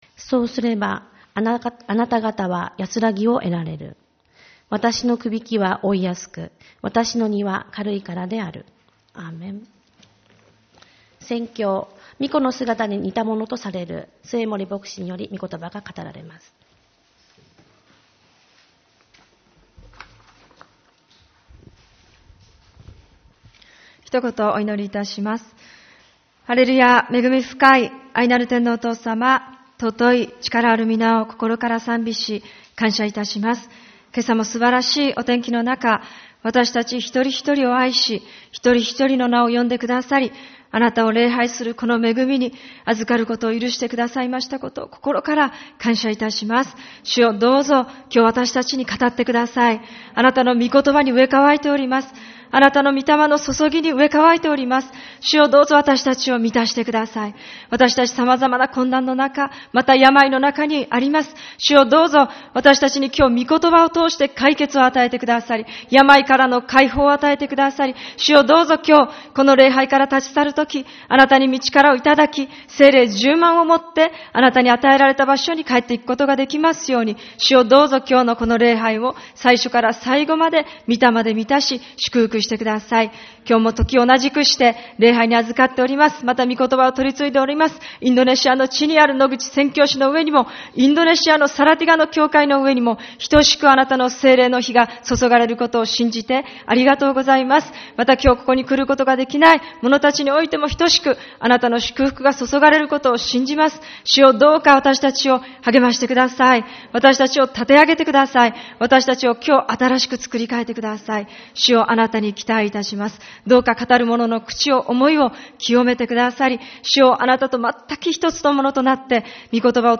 4月26日主日礼拝